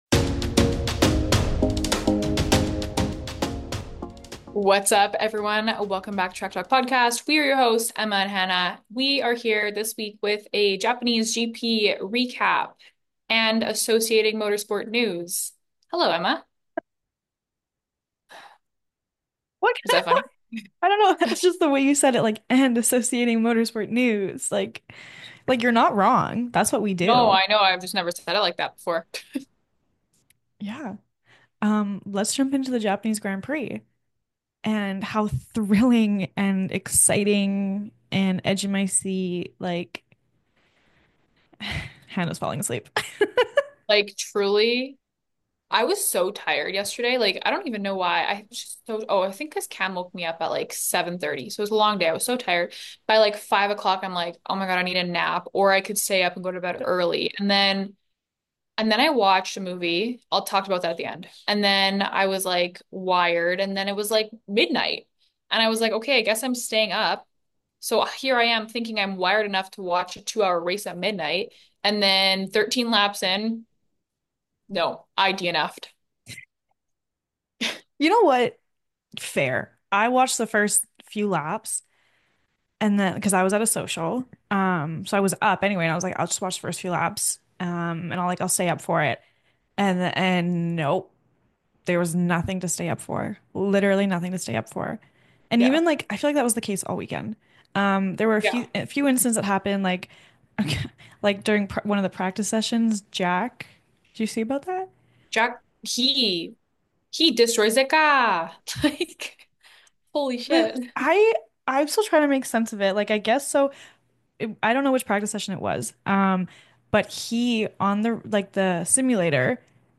Ford Performance: A Conversation with CEO Jim Farley – Track Talk Podcast – Podcast